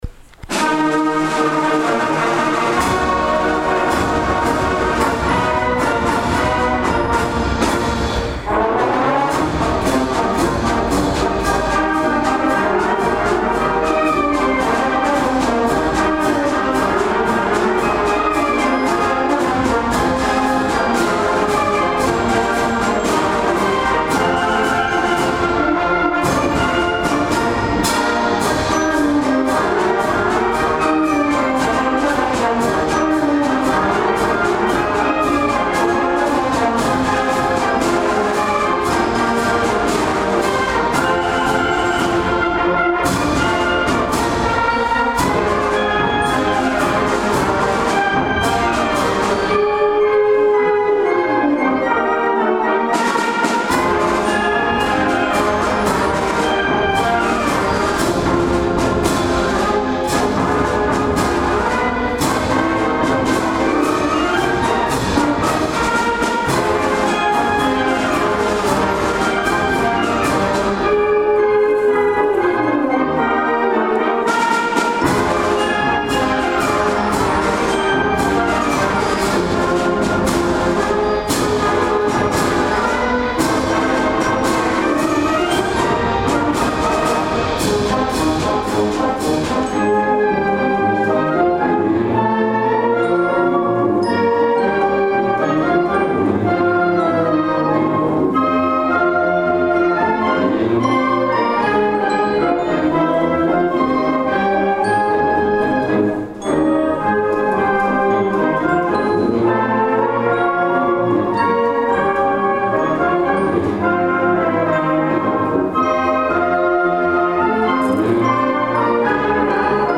KarlKing_SellsFlotoTriumphalMarch.MP3